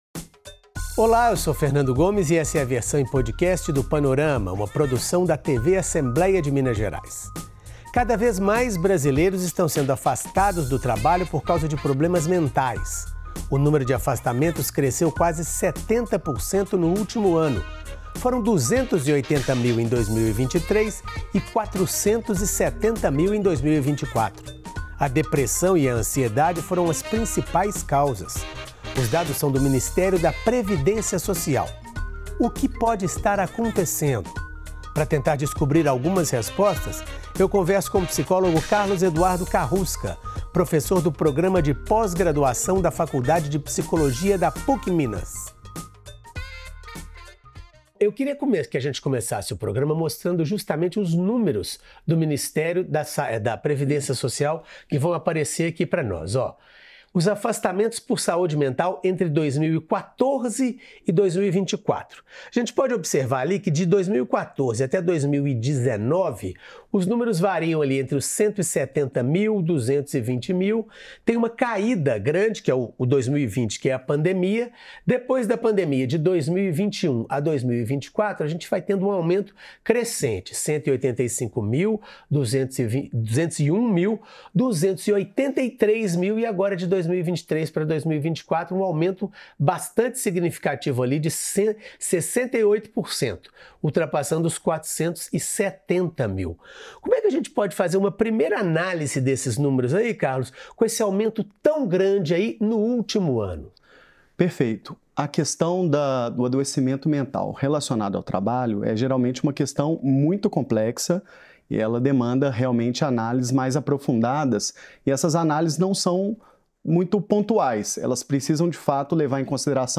conversa com o psicólogo